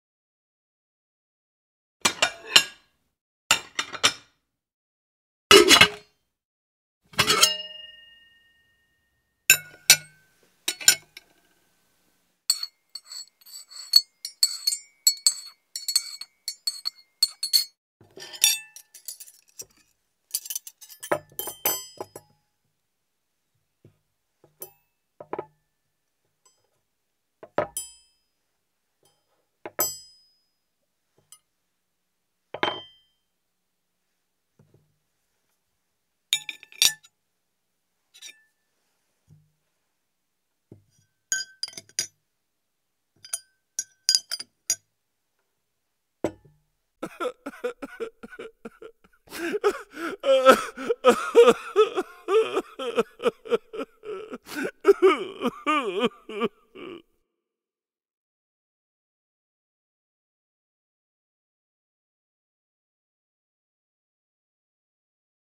دانلود آهنگ بشقاب 1 از افکت صوتی اشیاء
جلوه های صوتی
دانلود صدای بشقاب 1 از ساعد نیوز با لینک مستقیم و کیفیت بالا